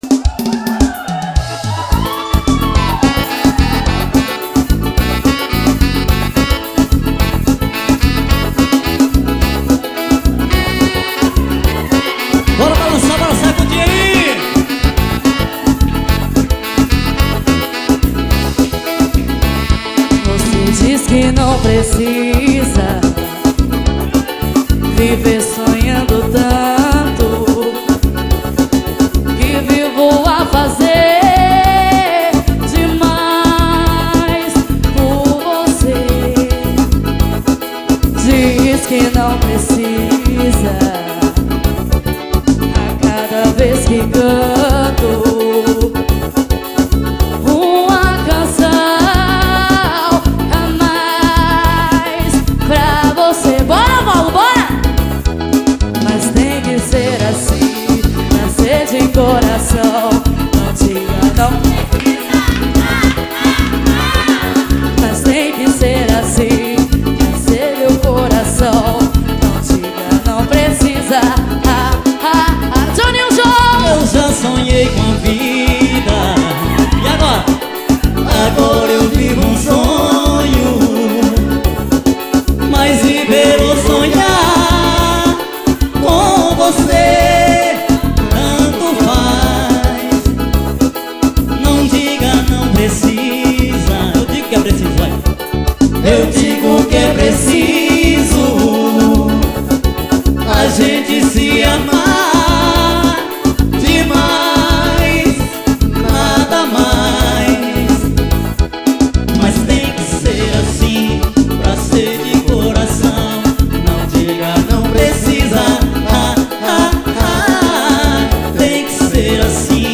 DVD AO VIVO